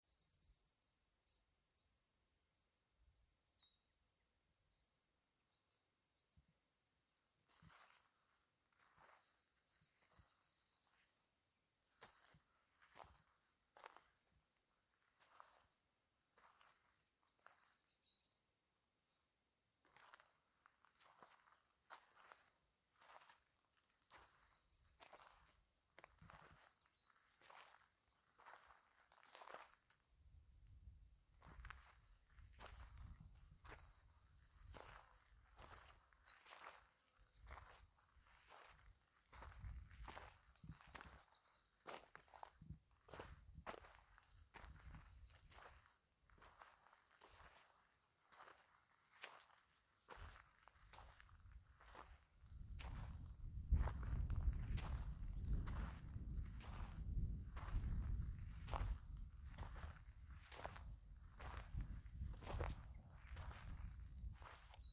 Tufted Tit-Spinetail (Leptasthenura platensis)
Spanish Name: Coludito Copetón
Location or protected area: Santa María
Condition: Wild
Certainty: Photographed, Recorded vocal
coludito-copeton-mp3.mp3